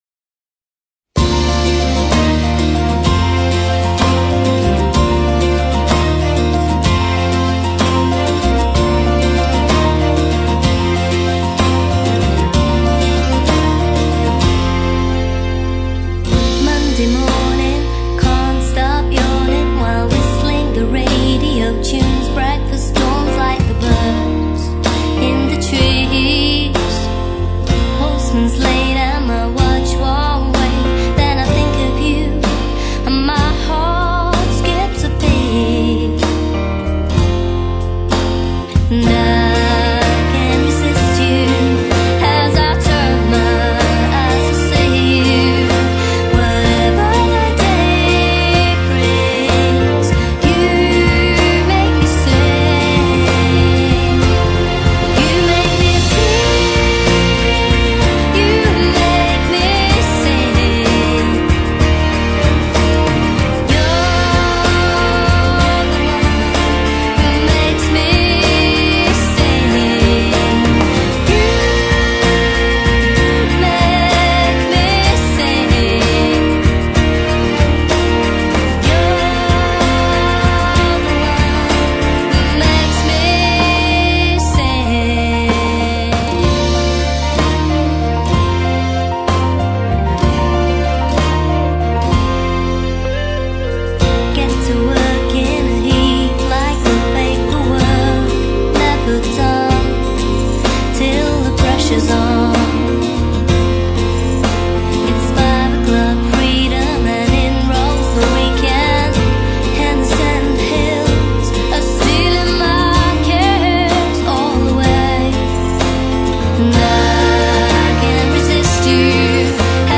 piano based singer /songwriter